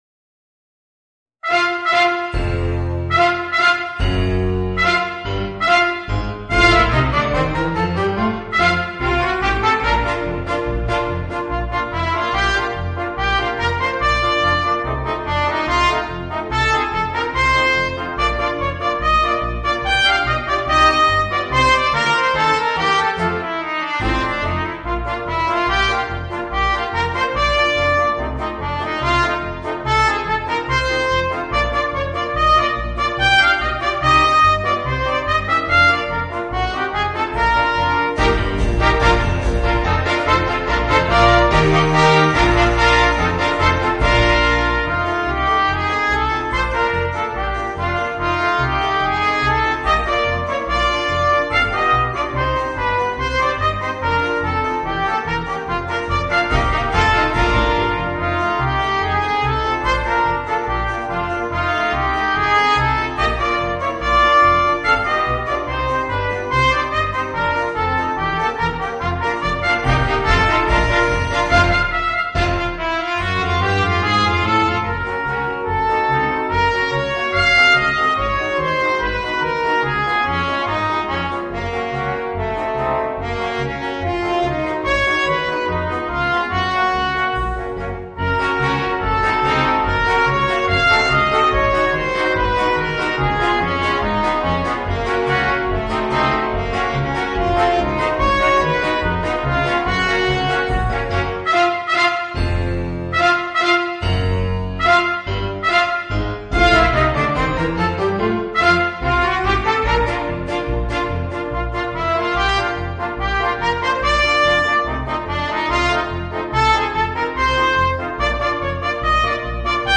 Voicing: 2 Trumpets, 2 Trombones and Piano